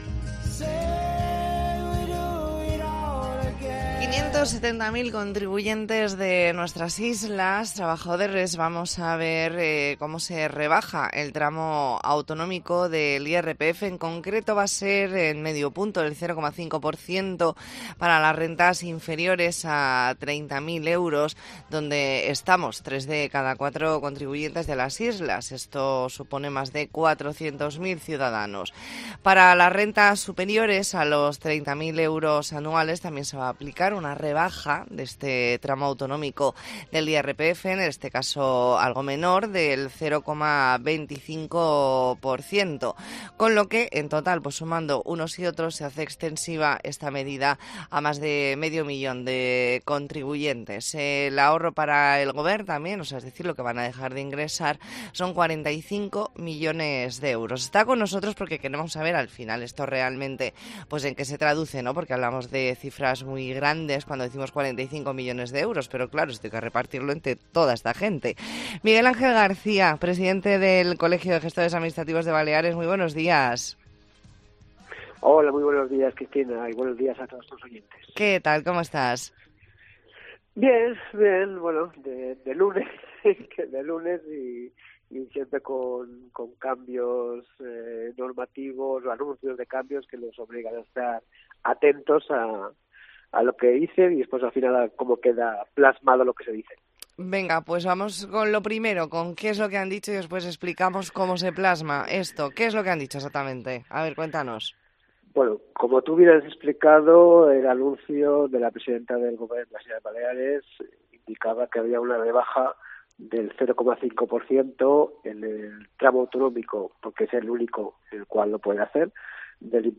Entrevista en La Mañana en COPE Más Mallorca, lunes 13 de noviembre de 2023.